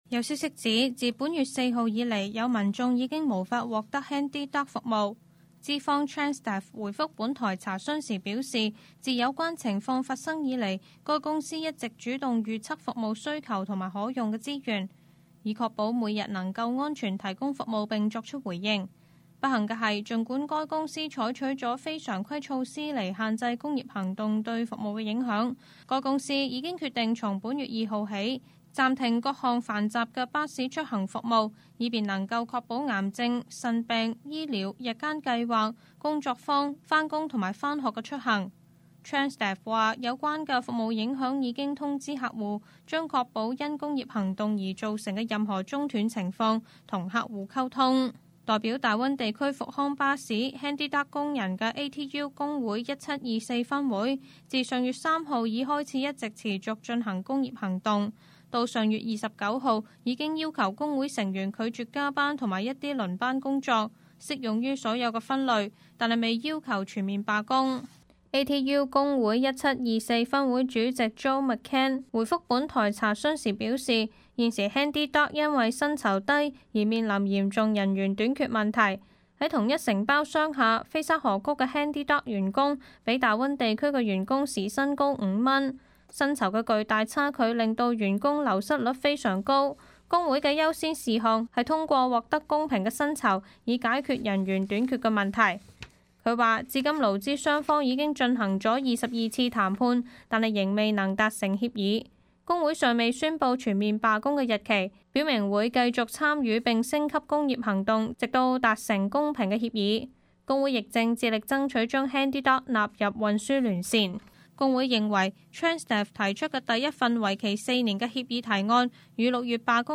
Local News 本地新聞